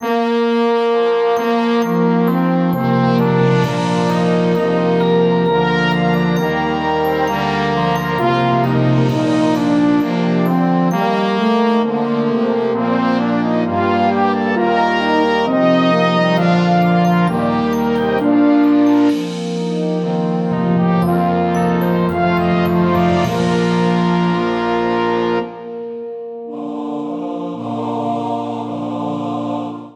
für Jodelgesang und Brass Band
Beschreibung:Volksmusik; Blasmusik; Jodel; Jodellied
Besetzung:Brass Band, Jodelgruppe (Chor, Duett, Solo)